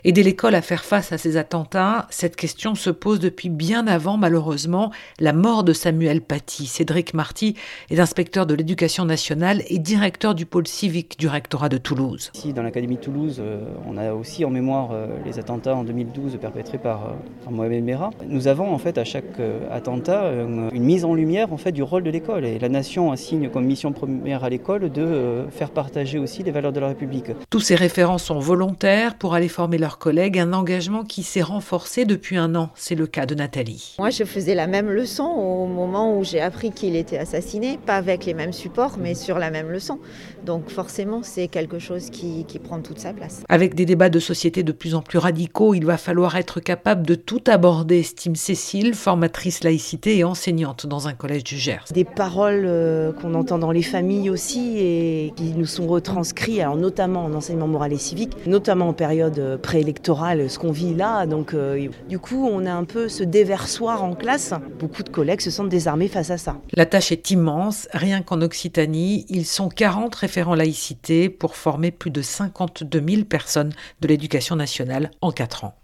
AUDIO / "Une mise en lumière du rôle de l'école, je faisais la même leçon quand j'ai appris son assassinat" - Reportage